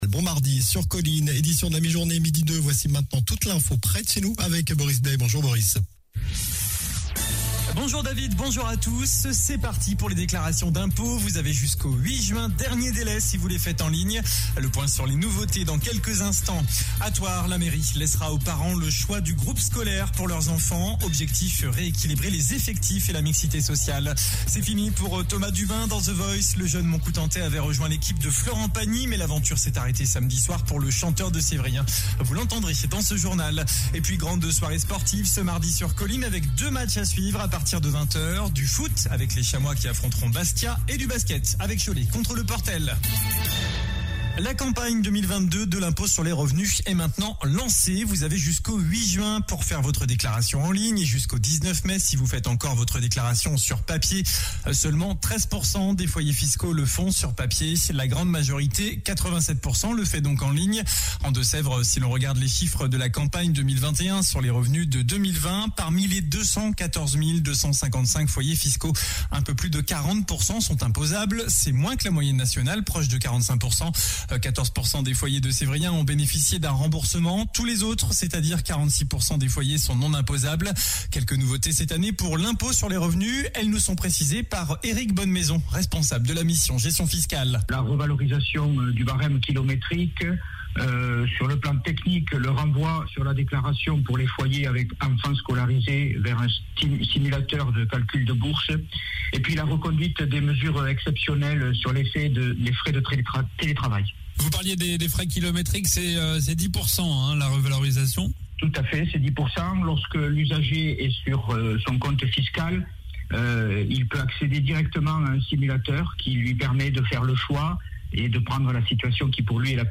Journal du mardi 19 avril (midi)